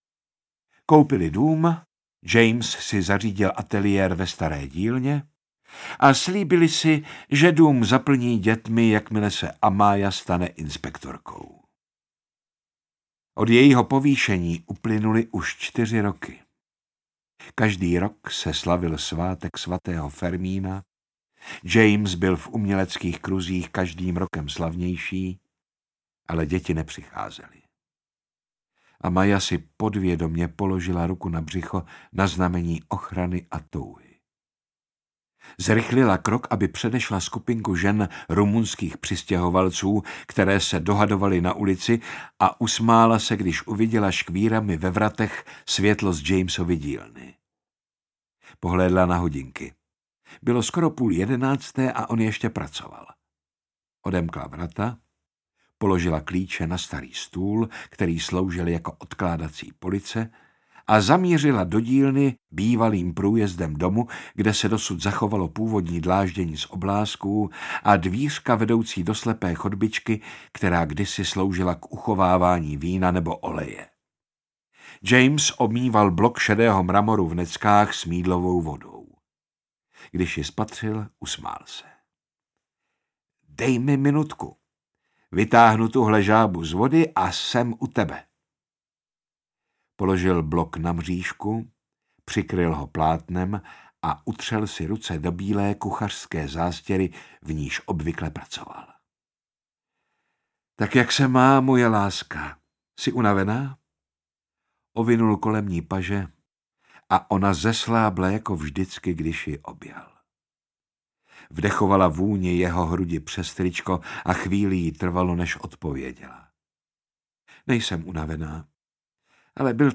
Neviditelný strážce audiokniha
Ukázka z knihy